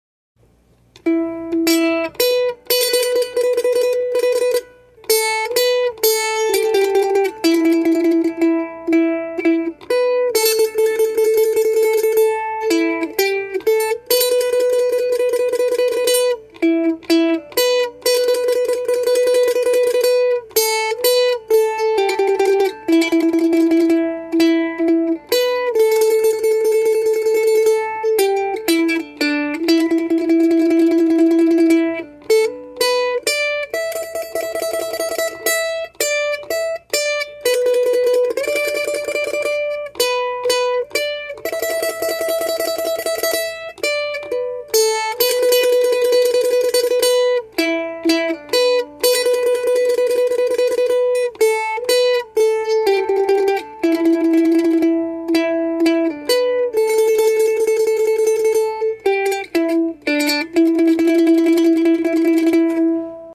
Key: Em
Form: Slow Air/song/spiritual
Region: USA, Southern